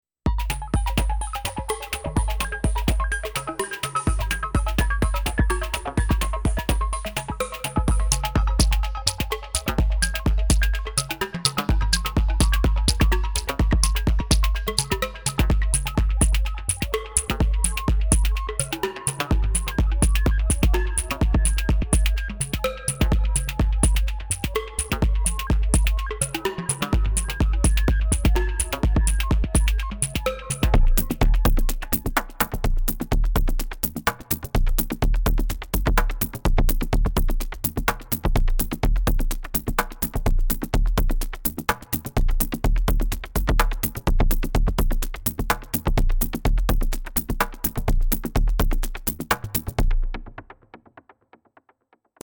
Moving more slowly here with some melodies and drums.
One that sounds like a short “rainstick”, that one dope man!
Second one: I really like the beginning with the ‘shimmery waterfall’ sound :smiley: